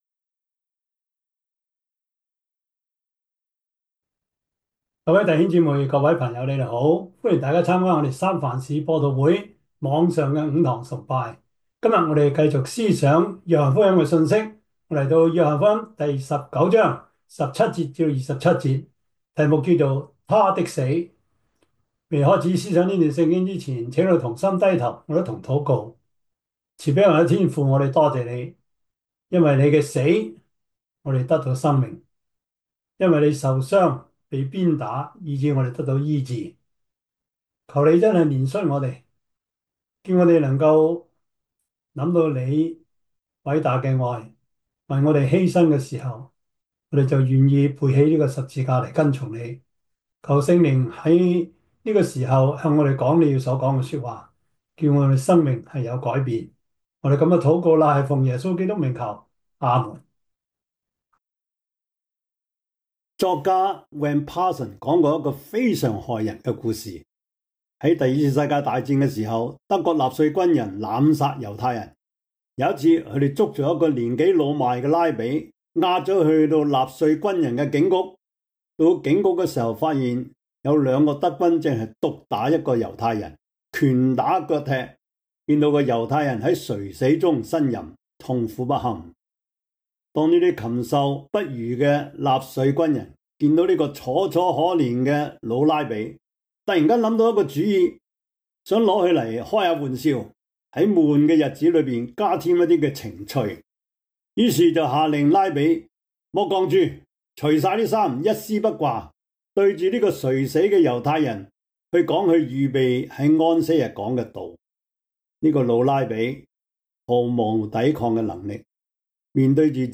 約翰福音 19:17-27 Service Type: 主日崇拜 約翰福音 19:17-27 Chinese Union Version